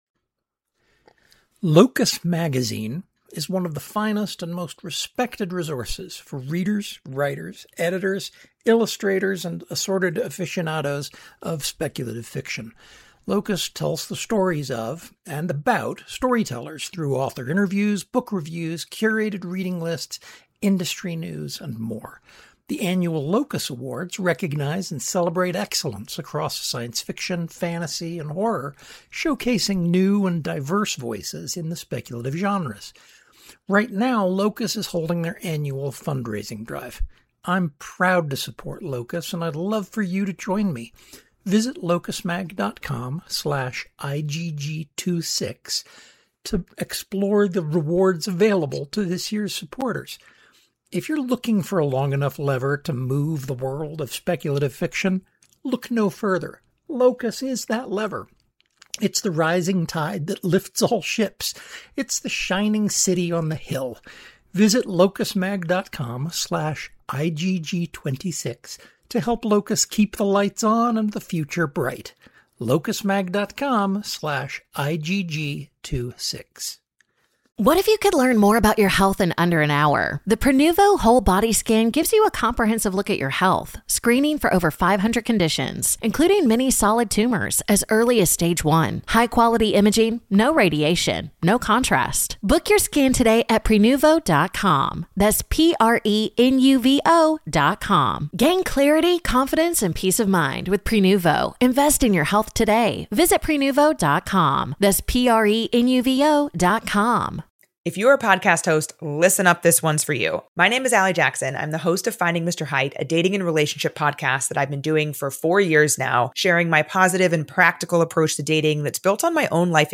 this episode was recorded in Cosmere House Studios